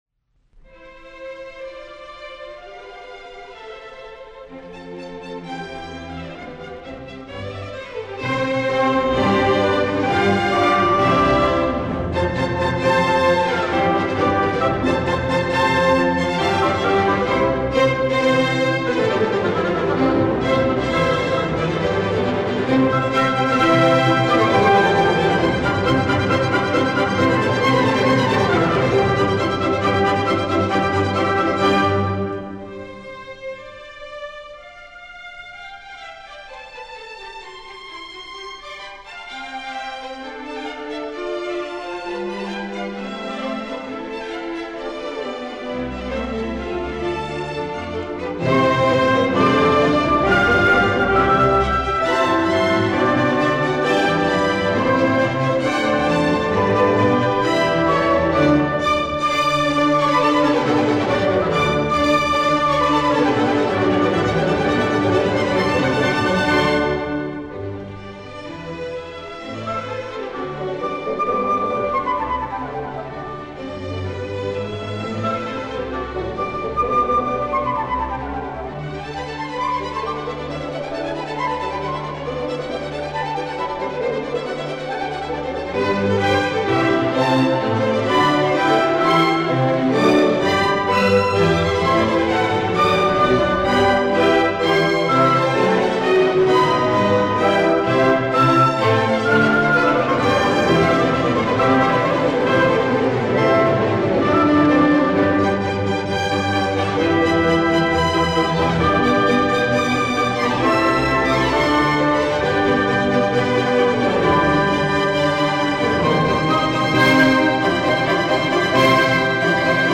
The Jupiter is often (mis-)named as the Symphony with the Fugal Finale but, despite its seemingly fugal opening, this is quickly abandoned for a more characteristic working out of the themes. He pulls together the themes from early movements of the symphony to create a beautifully syncretic finale that concludes in a magnificent climax.
At the end, Mozart takes the five themes he started with and combines them, culminating in all five themes being heard simultaneously.
This recording was made in 1955 by the Bamberg Symphony Orchestra under the direction of Jonel Perlea.
Bamberg Symphony Orchestra